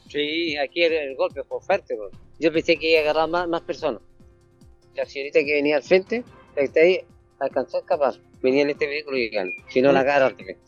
Uno de los transeúntes que divisó la escena, dijo que una conductora alcanzó a correrse para no ser chocada.